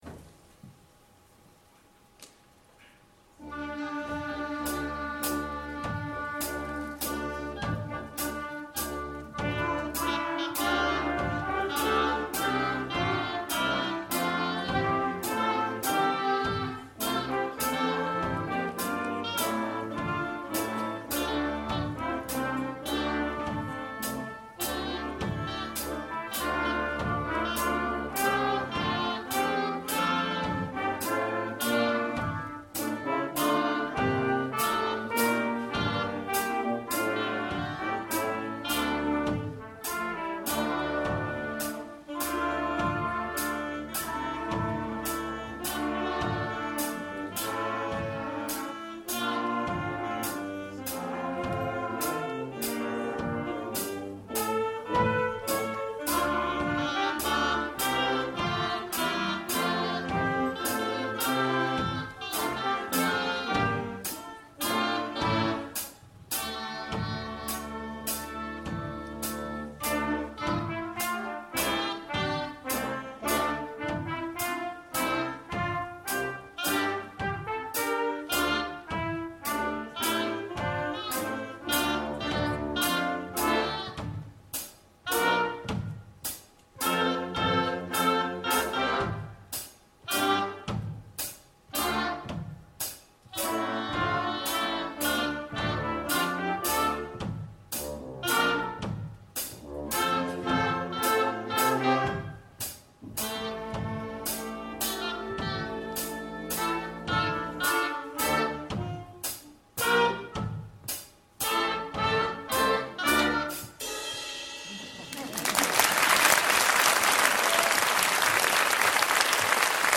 Primary Wind Band
Performed at the Autumn Concert, November 2014 at the Broxbourne Civic Hall. Pirates and Drunken Sailor